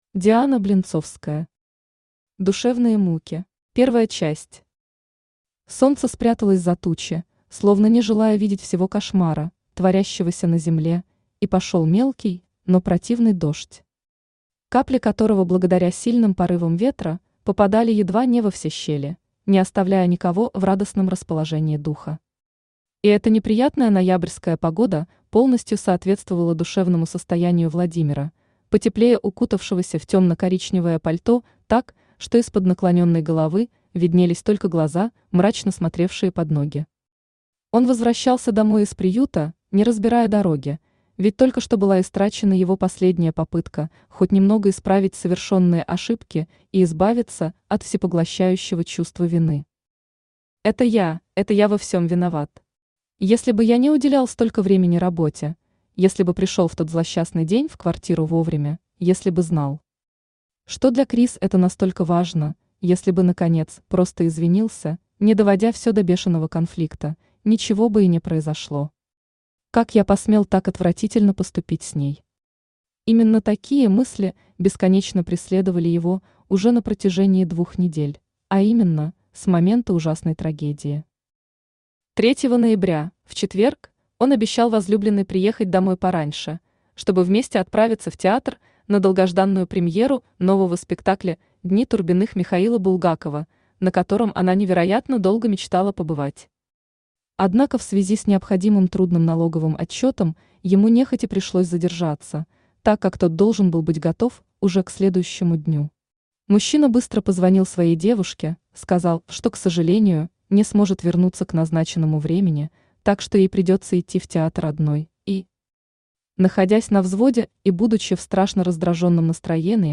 Aудиокнига Душевные муки Автор Диана Константиновна Блинцовская Читает аудиокнигу Авточтец ЛитРес.